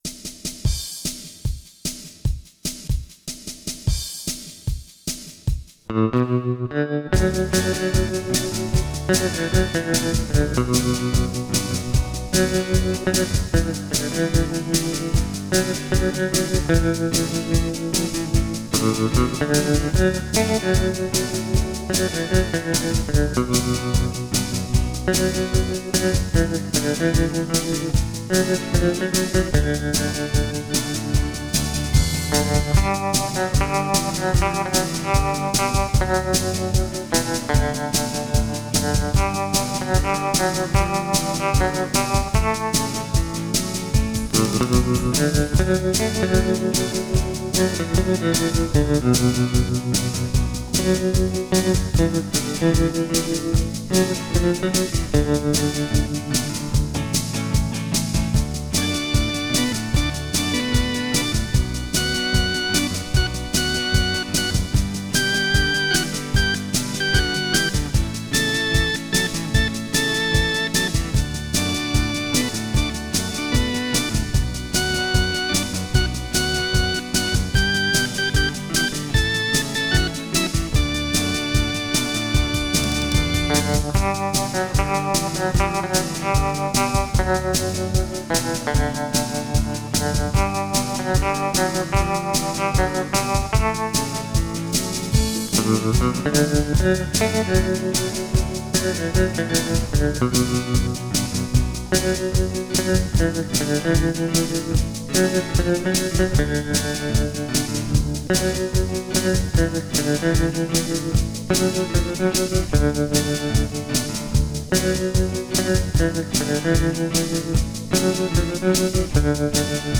Rozhodl jsem se k vlastní úpravě v duchu Gibson/retro/archtop. Kytara je vybavena dvěma minihumbuckery Gibson.
Zvuk kytary je díky použitým snímačům a kvalitnímu korpusu nádherně čistý, jazzový.